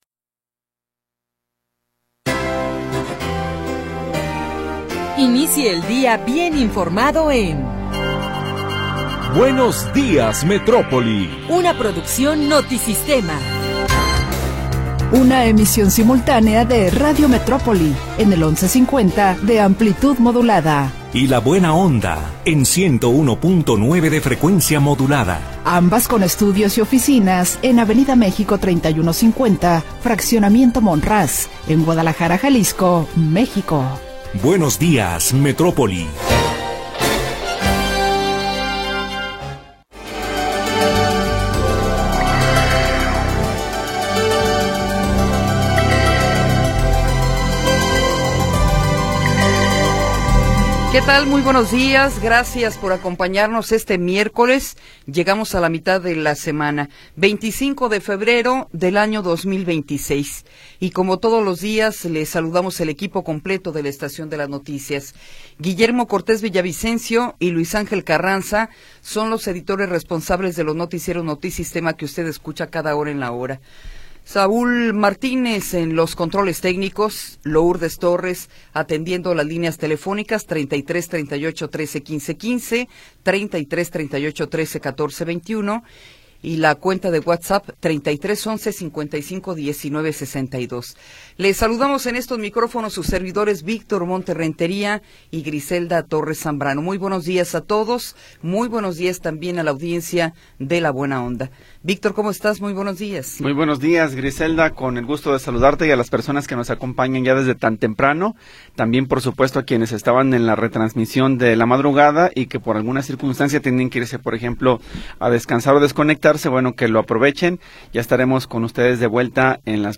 Primera hora del programa transmitido el 25 de Febrero de 2026.